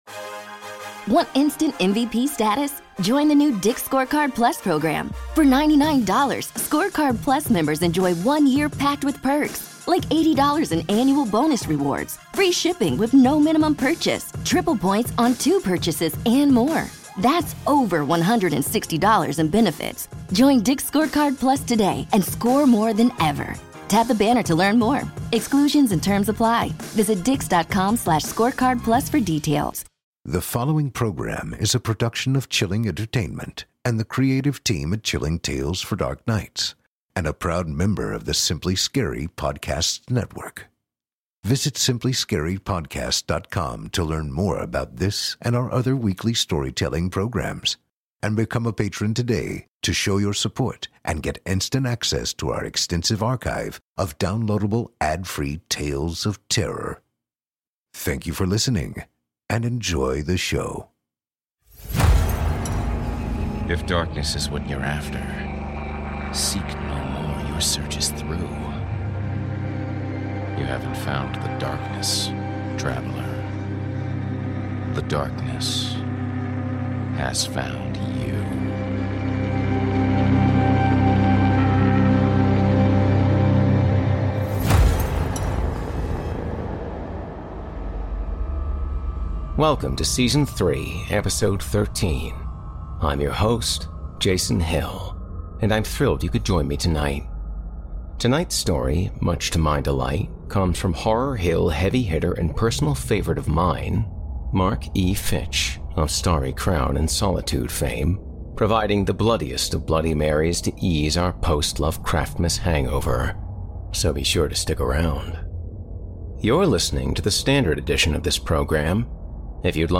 a feature-length tale